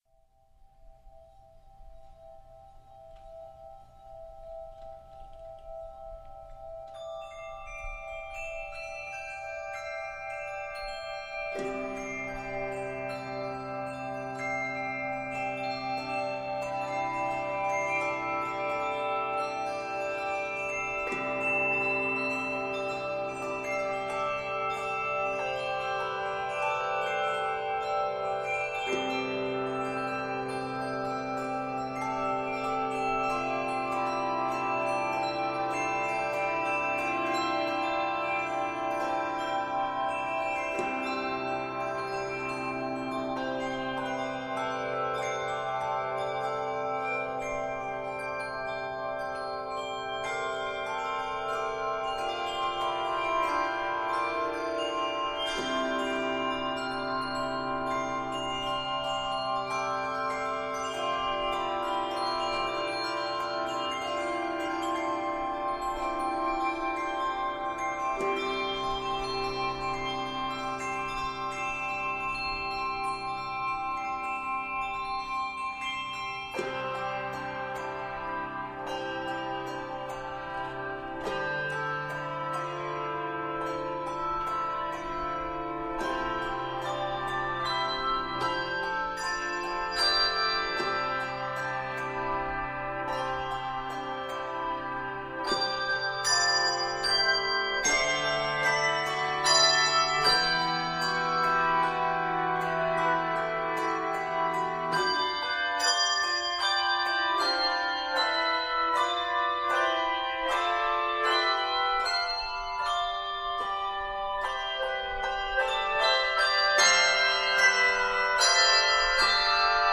Pentecost hymn
It is set in C Major and D Major.